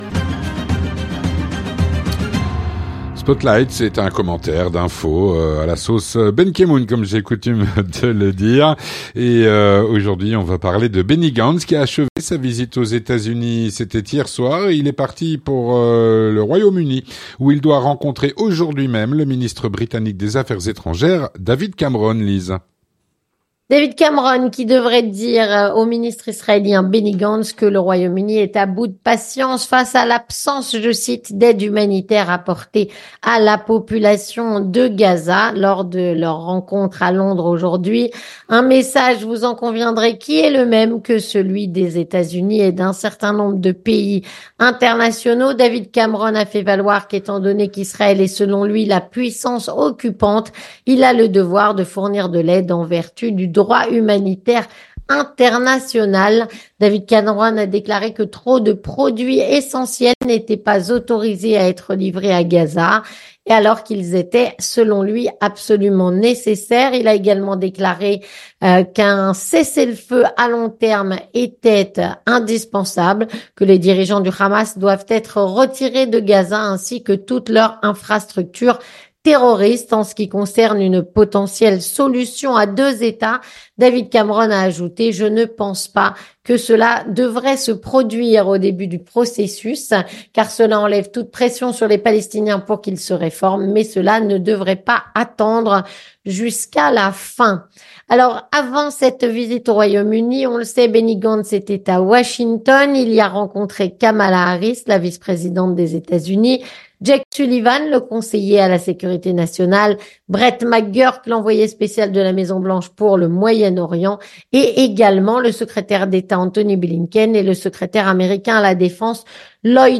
3. Les Chroniques de la Matinale